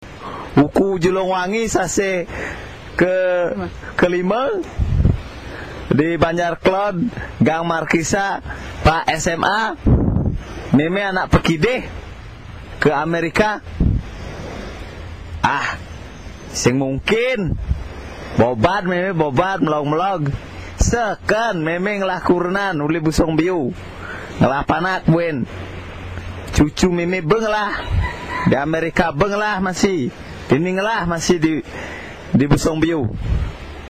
Here’s a recording of someone speaking a mystery language.
I’m not sure what this language is beyond a Caribbean pidgin, but the man in this audio clip is most certainly drunk and rambling about America.
It sounds like a French creole language, but I also recognize some phrases/words which are also used in Suriname (base language Dutch).